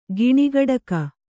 ♪ giṇi gaḍaka